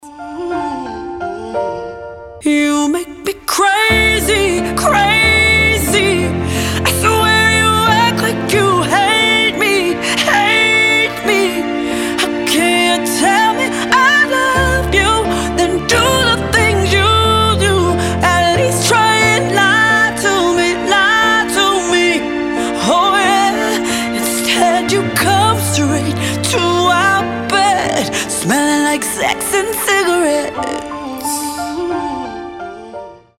• Качество: 320, Stereo
чувственные
пианино
красивый женский вокал
медлячок
soul
сильный голос